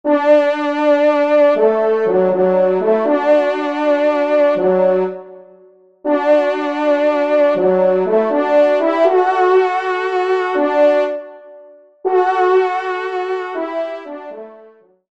Genre : Fantaisie Liturgique pour quatre trompes
Pupitre 2ème Trompe